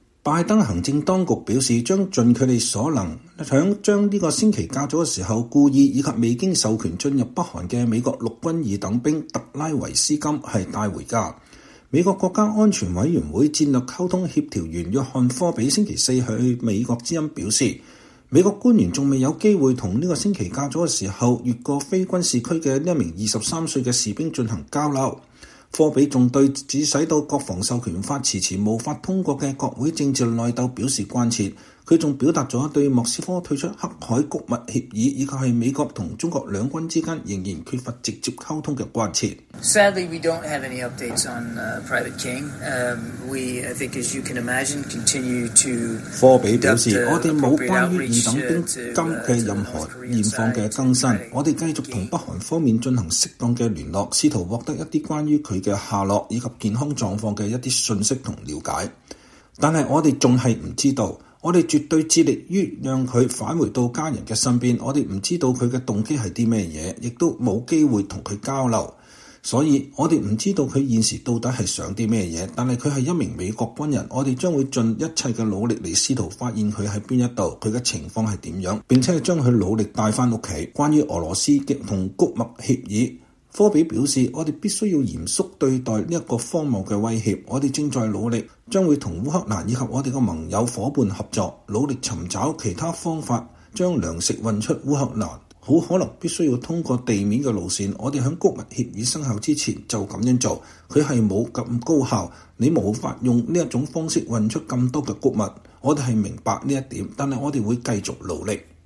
VOA專訪國安會發言人：美中兩軍溝通仍然關閉，“這是不幸的”